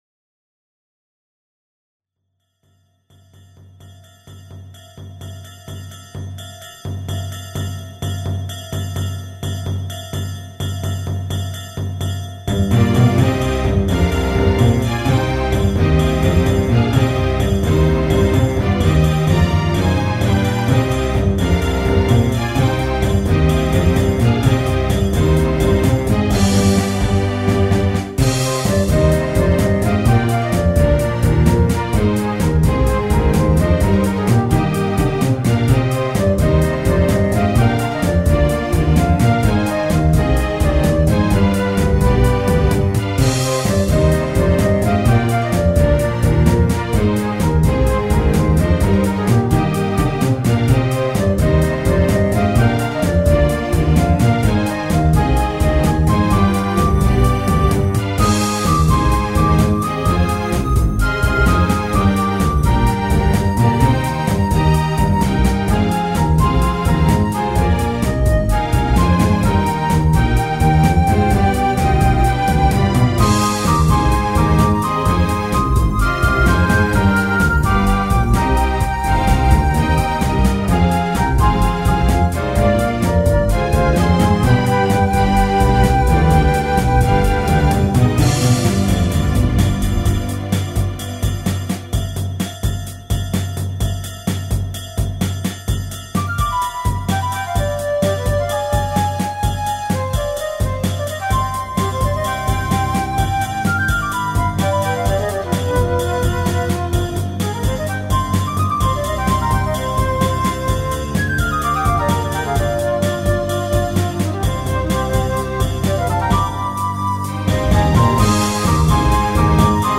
ファンタジーロック民族